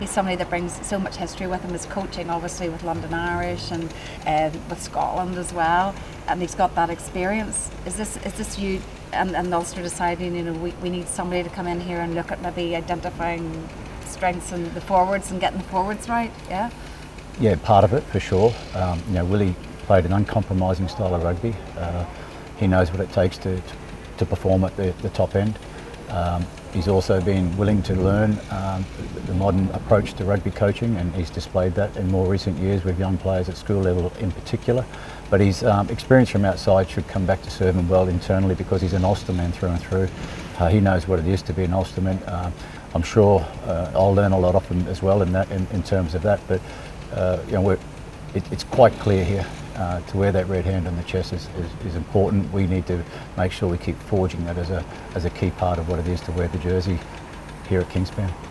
U105 Sport speaks to Ulster's Les Kiss